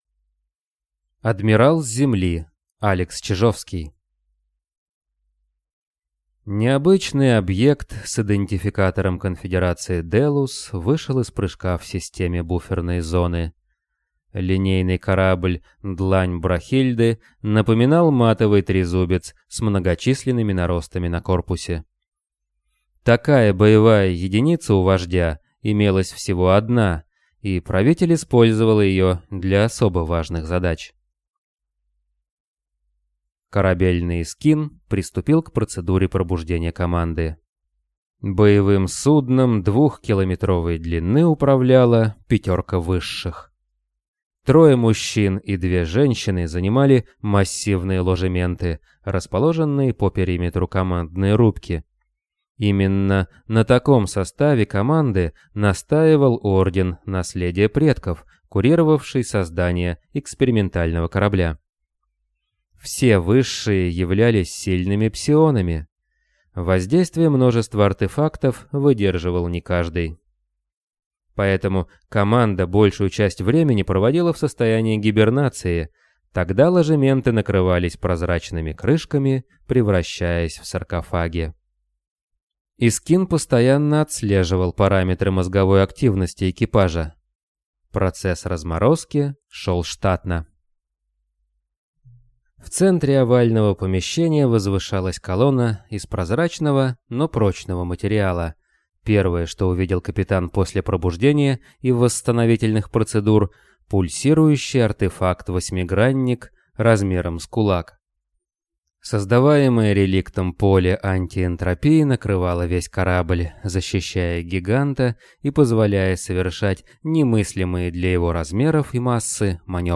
Аудиокнига Адмирал с Земли | Библиотека аудиокниг
Прослушать и бесплатно скачать фрагмент аудиокниги